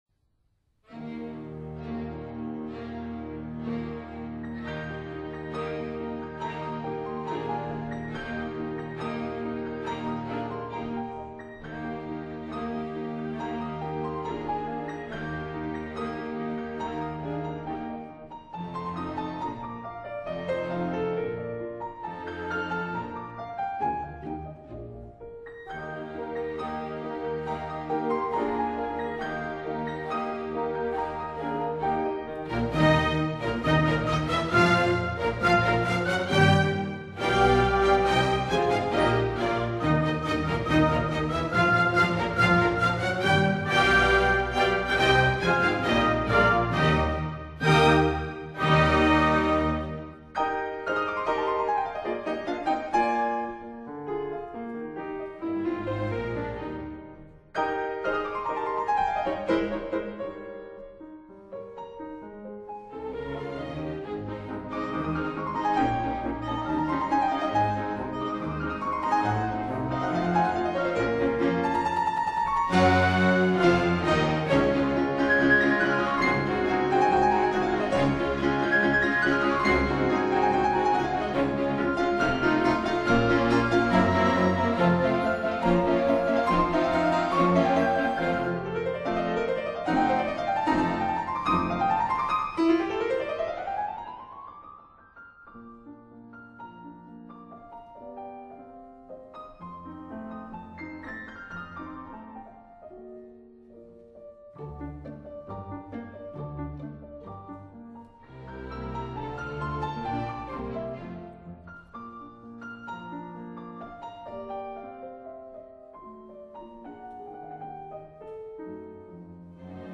菲爾德的鋼琴協奏曲輕快美妙，搔的人心兒想飛